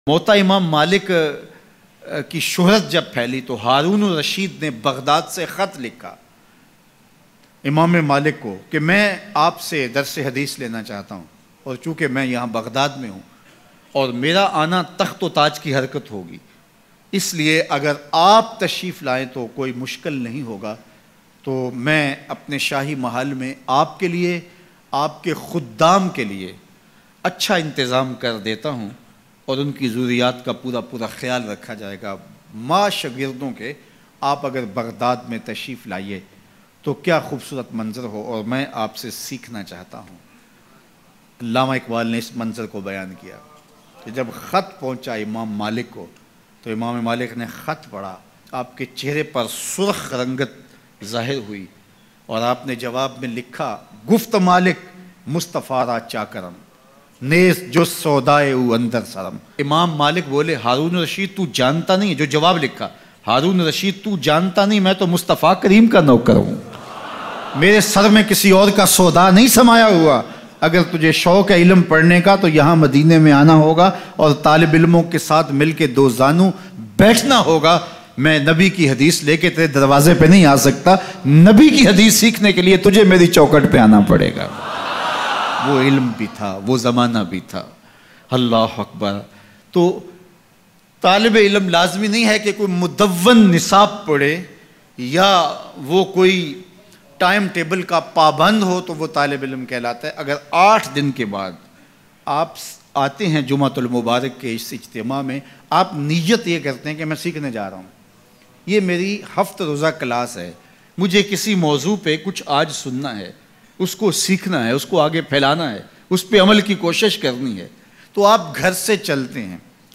BadShah ka Imam Maalik k naam khat Bayan